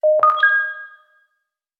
beep.wav